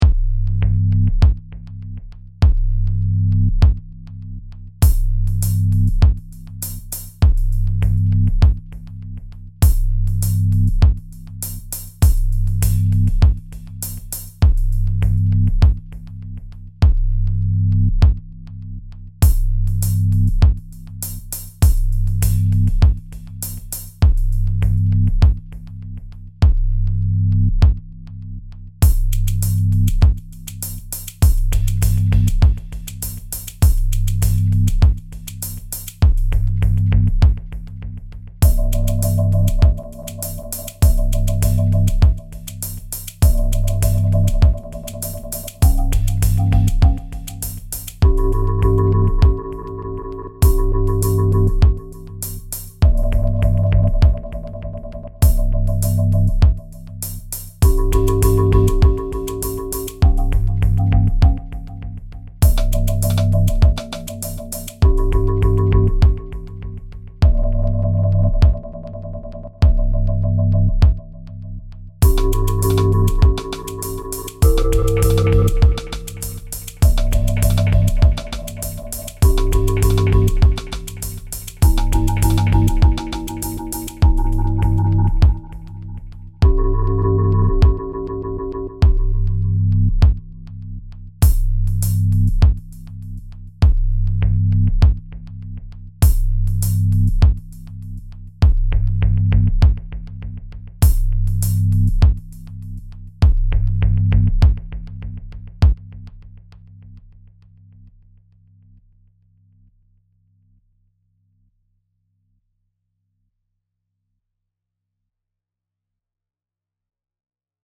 Pieza de Ambient Techno
Música electrónica
tecno
melodía
repetitivo
sintetizador